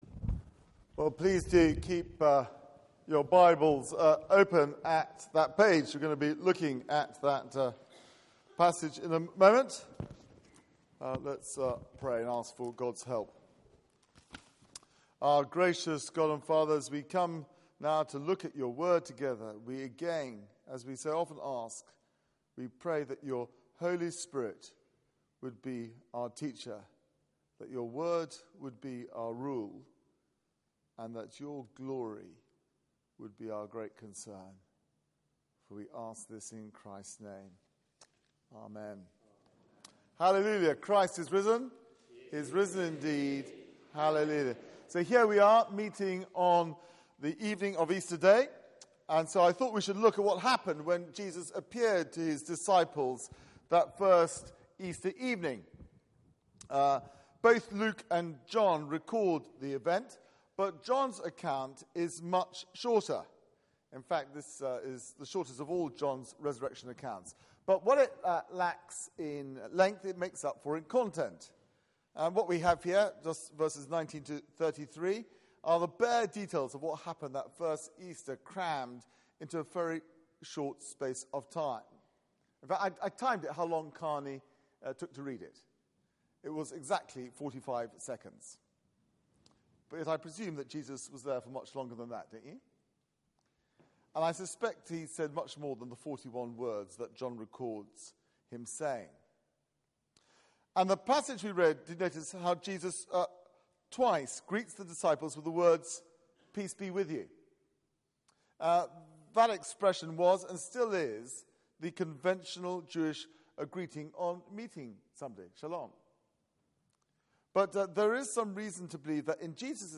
Media for 6:30pm Service on Sun 05th Apr 2015 18:30 Speaker
Series: Easter Day Theme: Why Easter changes everything Sermon Search the media library There are recordings here going back several years.